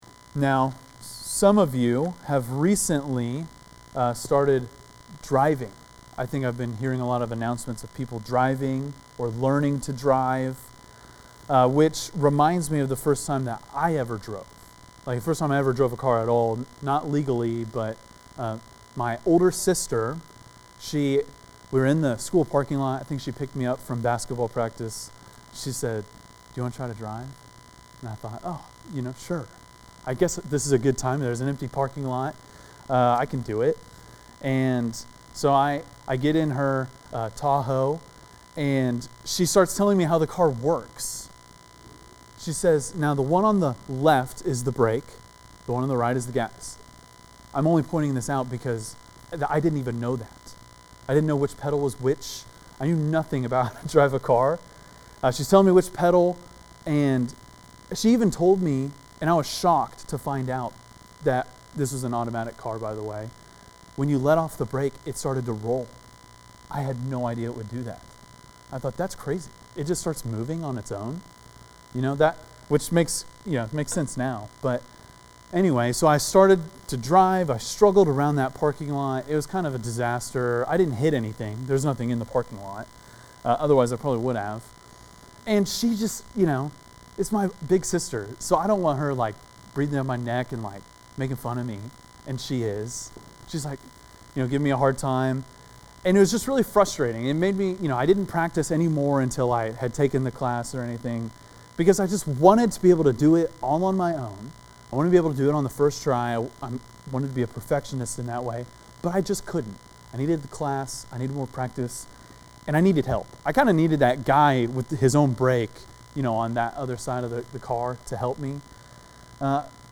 preaches through 1 Corinthians 16.